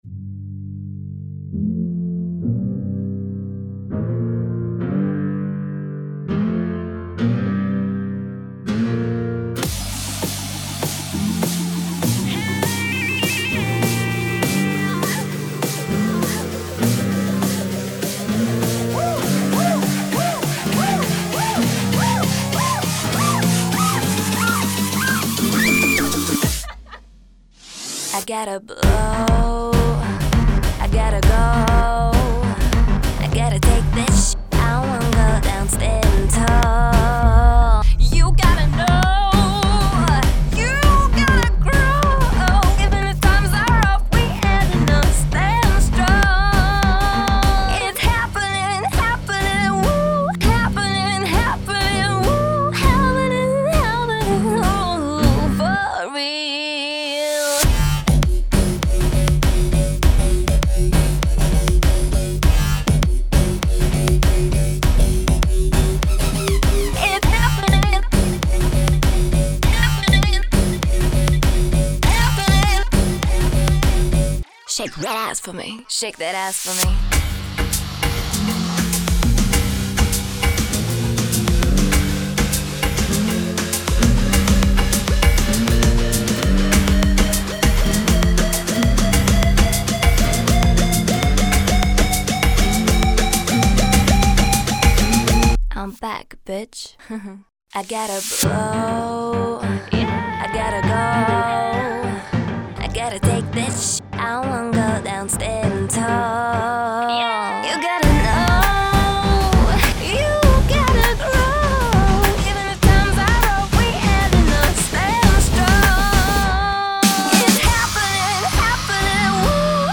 Style: EDM, Future Bass, Pop/Dance; Up-tempo